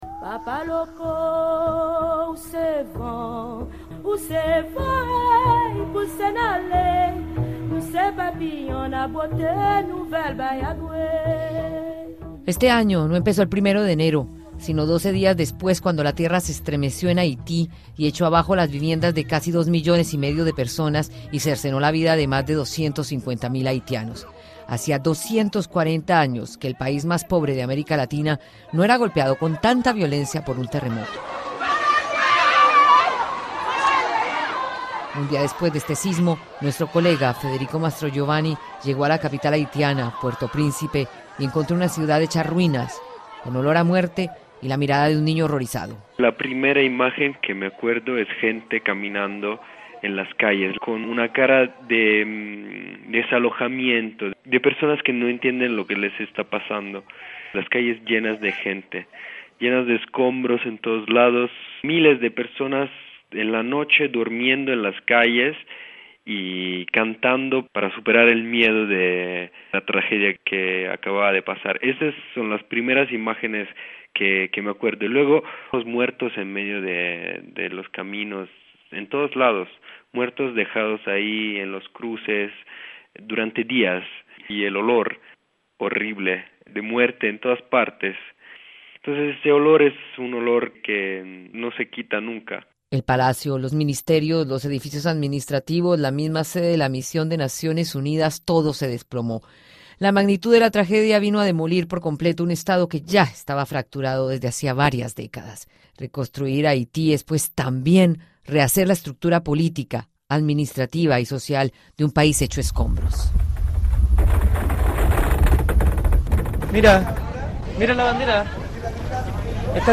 El terremoto en Haití y ahora el cólera, el rescate de los mineros en Chile y la muerte de Néstor Kirchner son algunos de los sucesos más destacados en América Latina en 2010. Escuche el informe de Radio Francia Internacional.